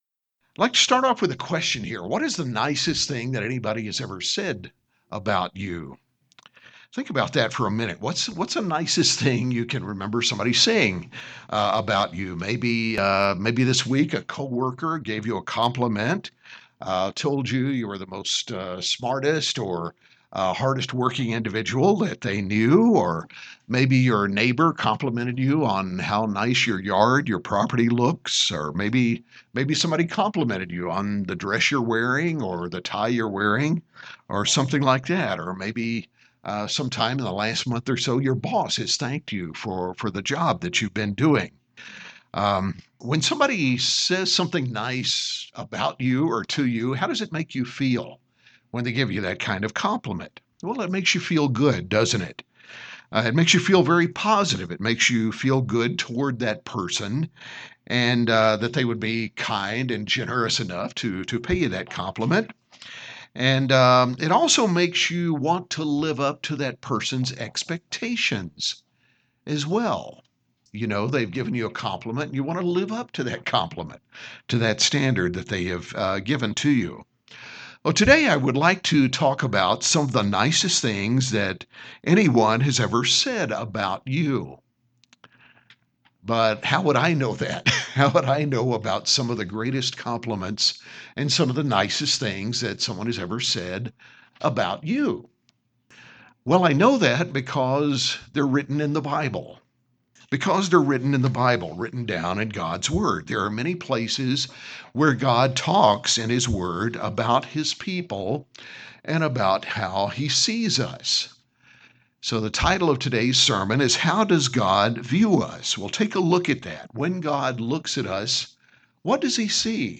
How does God view you? And how does he view the collective body that is His Church? In this sermon we examine a number of passages that show what God sees in us both now and in the future, shedding light on His wonderful plan for us.